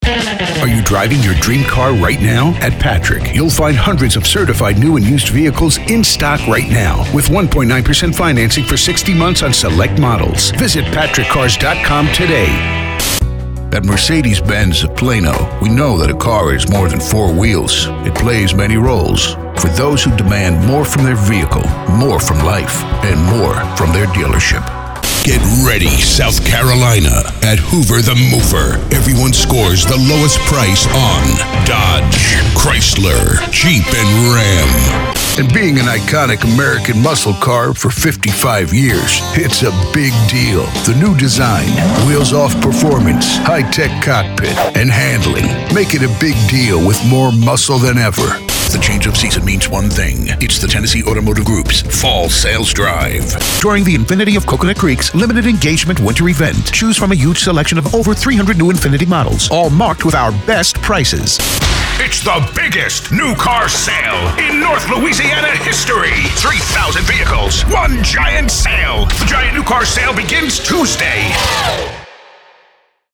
Deep, Epic, Motivational.
Automotive